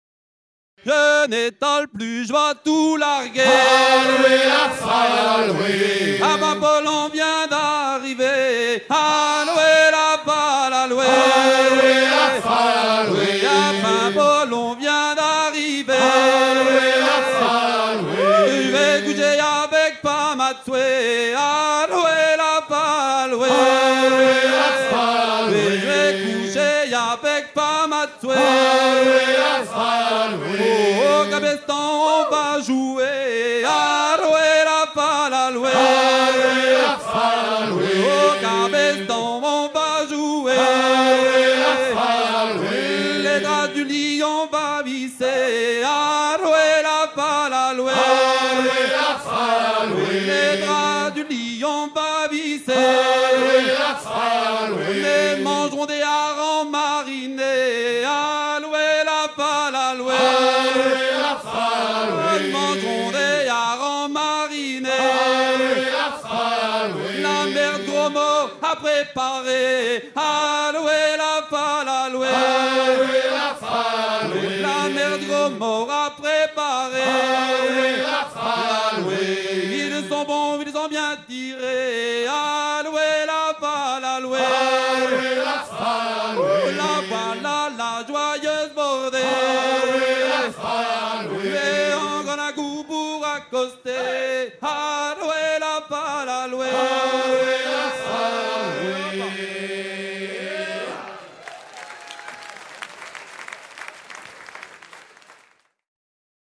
Paroles improvisées sur la mélodie d'un chant de halage fécampois recueilli dans les années 1970
Chants de marins en fête - Paimpol 1999
Pièce musicale éditée